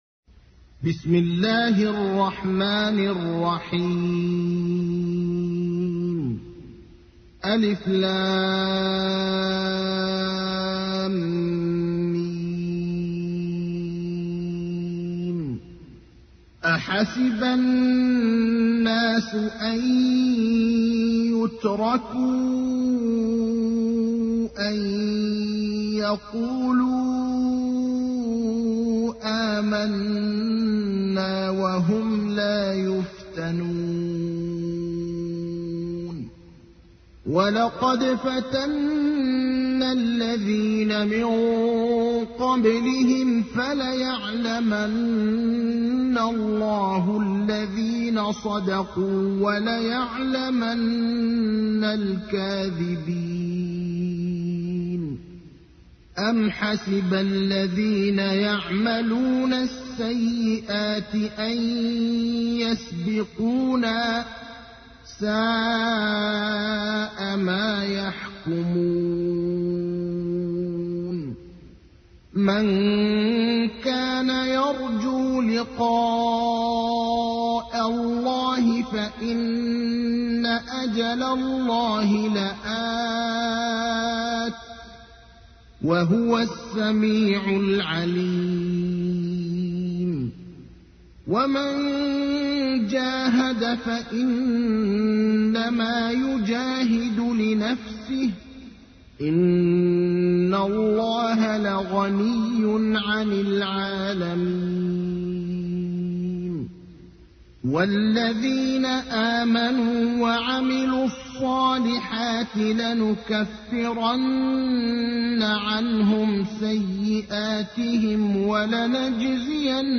تحميل : 29. سورة العنكبوت / القارئ ابراهيم الأخضر / القرآن الكريم / موقع يا حسين